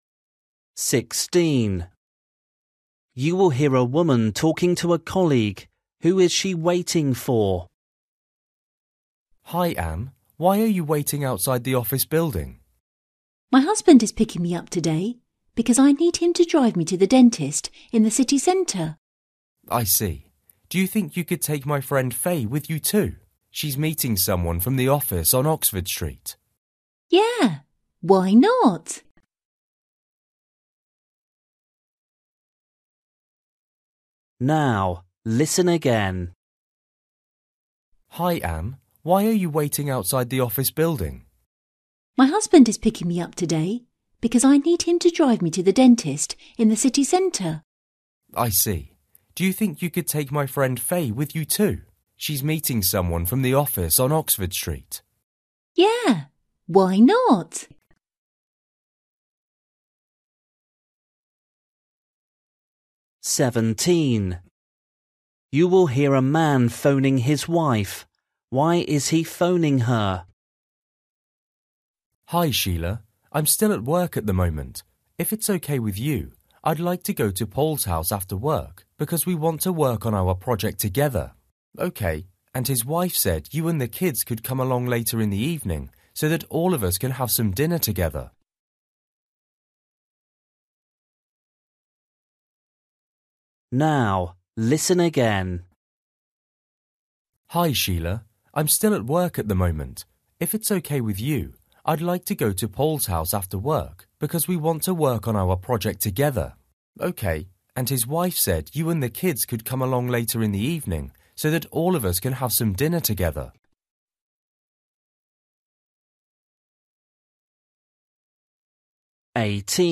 16   You will hear a woman talking to a colleague.
17   You will hear a man phoning his wife.
18   You will hear a couple talking about a new car.